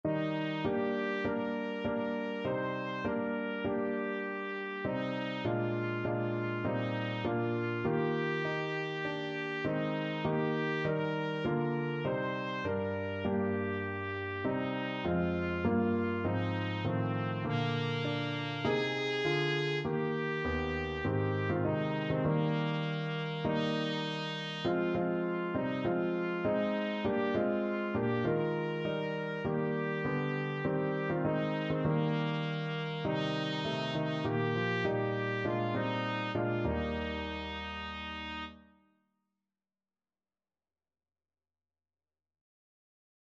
Christmas Christmas Trumpet Sheet Music Go Tell It On The Mountains
Trumpet
Eb major (Sounding Pitch) F major (Trumpet in Bb) (View more Eb major Music for Trumpet )
4/4 (View more 4/4 Music)